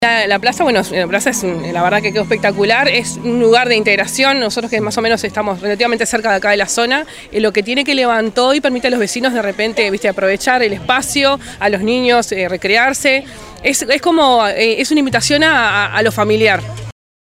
El sábado 13 de agosto, vecinas y vecinos del Municipio de Nicolich disfrutaron de una tarde de actividades recreativas y culturales en el marco de la inauguración de las obras de acondicionamiento de la plaza Fraternidad.